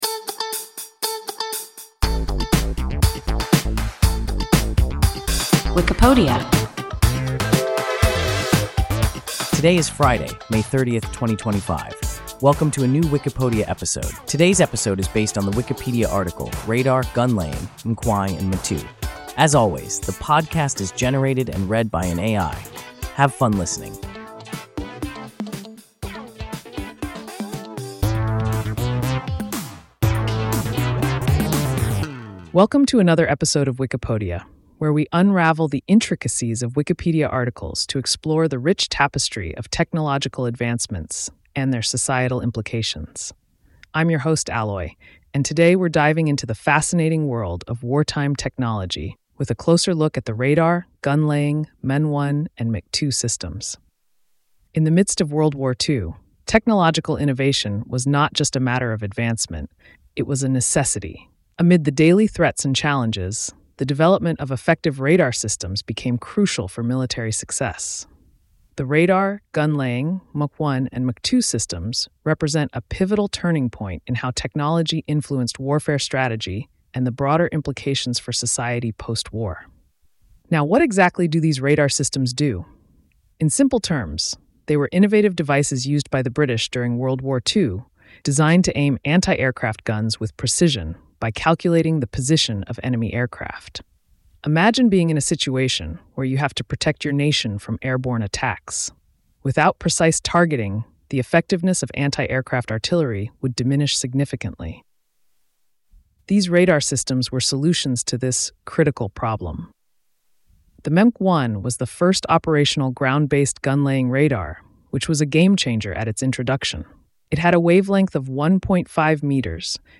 II – WIKIPODIA – ein KI Podcast